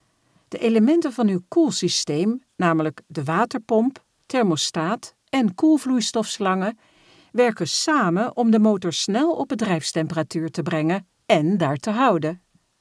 Voiceover voor luisterboeken, commercials, bedrijfsfilms, voice-response, audiotours, e-learning..De opnames worden gemaakt in mijn eigen professionele studio.
Stemvoorbeelden